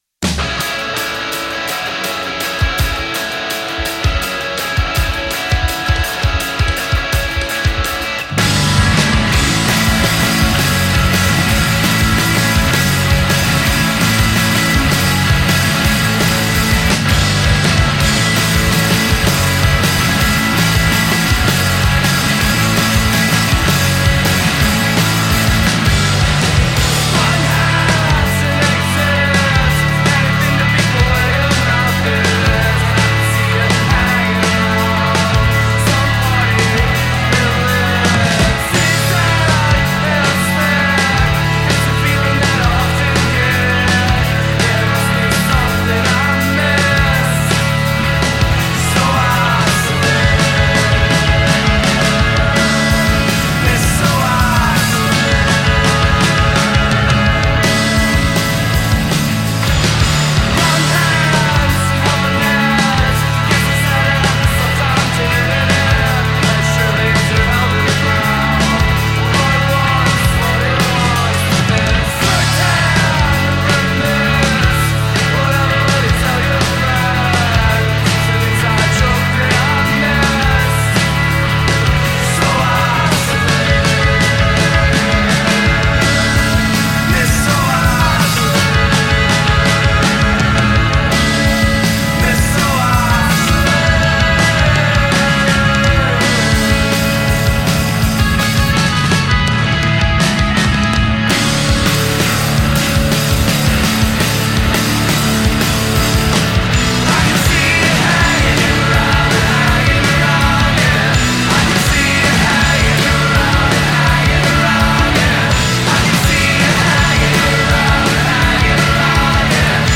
indie rock band